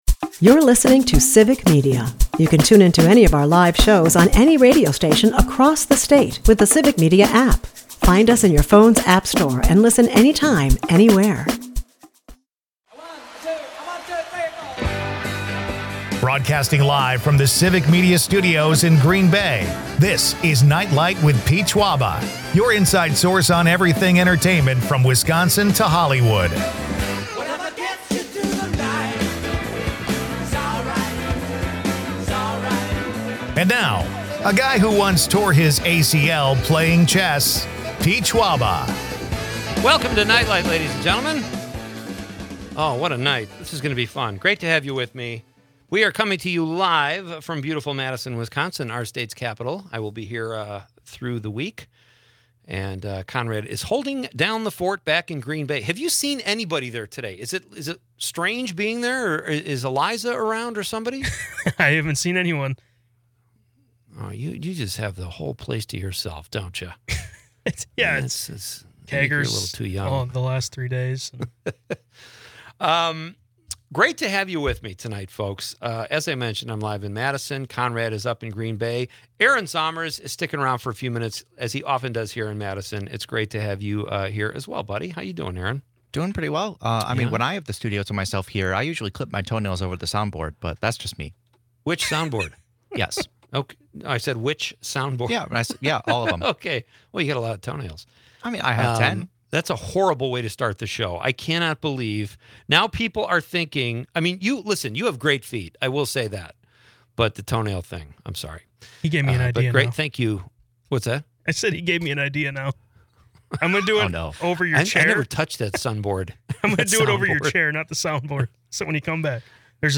The night’s hot topic: where does 'Up North' start in Wisconsin? Listeners chime in with varied geographic opinions, from Wausau to Highway 8.